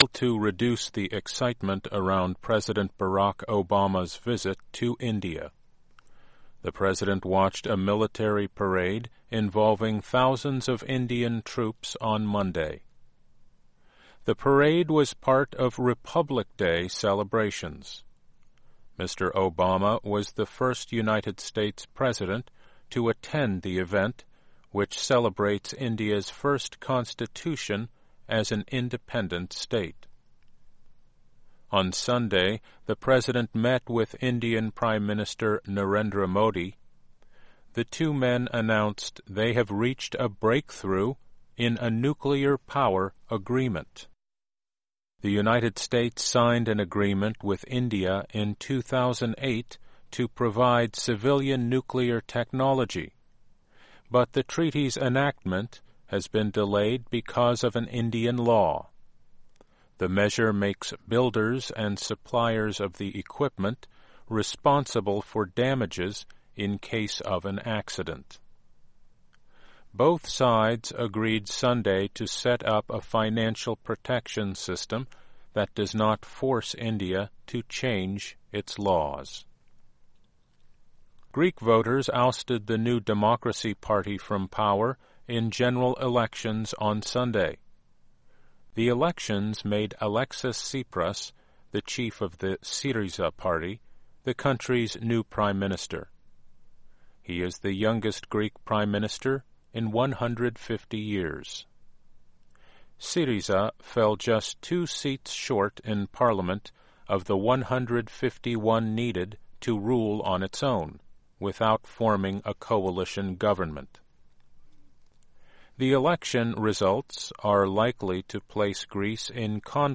This report was based on stories from VOA’s News Division.